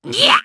Lakrak-Vox_Damage_jp_01.wav